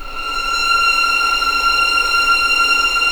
F6LEGPVLN  R.wav